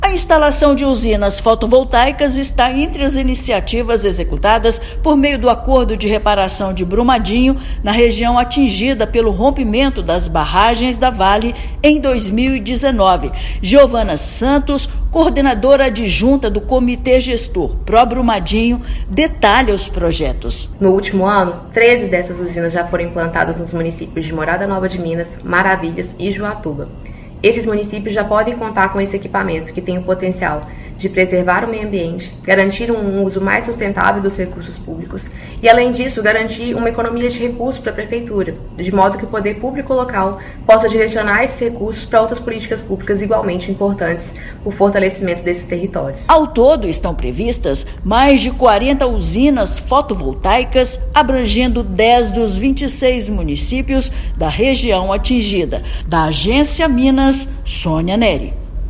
O projeto contempla dez dos 26 municípios atingidos pelo rompimento das barragens em Brumadinho. Foram instaladas 13 usinas em três municípios em 2024. Ouça matéria de rádio.